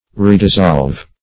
Redissolve \Re`dis*solve"\ (r?`d?z*z?lv"), v. t. To dissolve again.